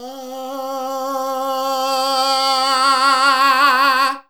AAAAH 2 C.wav